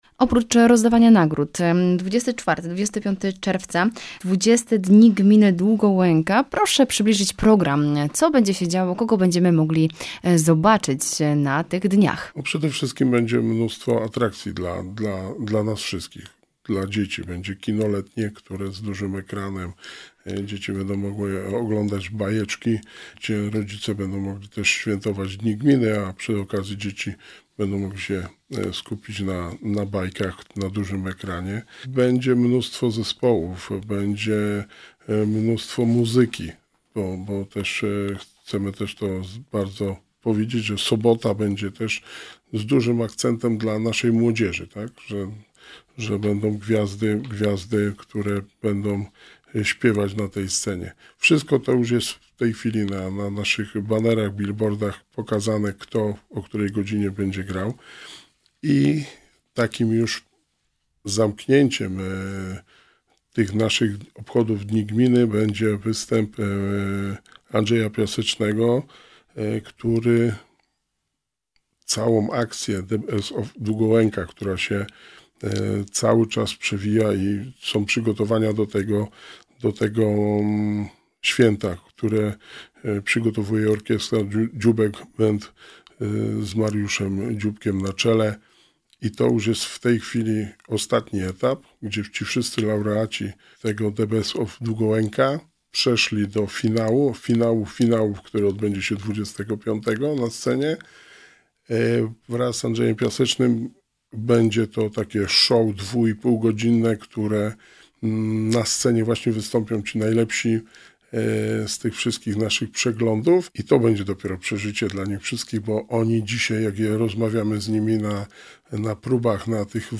W rozmowie Radia Rodzina z wójtem Gminy Długołęka – Wojciechem Błońskim rozmawiamy o bieżących wydarzeniach, planach inwestycyjnych oraz akcji „Oddaj Krew na wakacje”. Podsumowaliśmy także loterię „Rozlicz PIT w gminie Długołęka”.
02-wojt-gminy-rozmowa.mp3